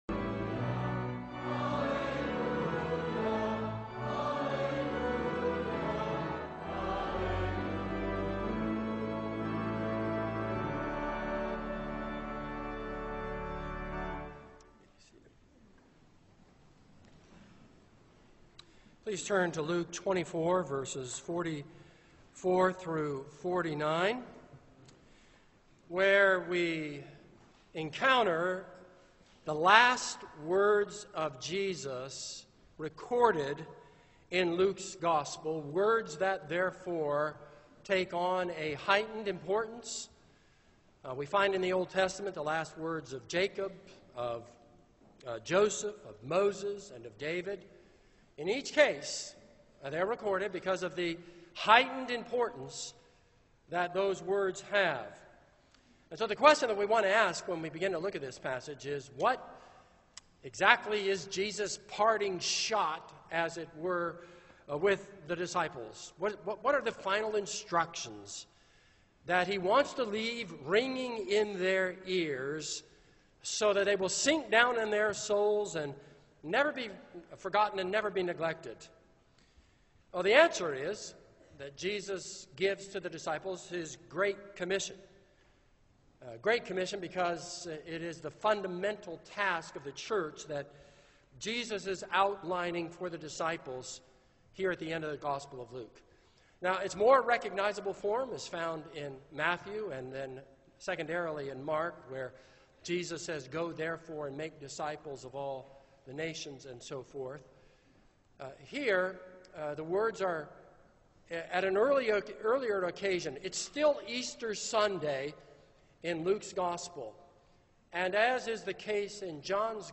This is a sermon on Luke 24:44-49.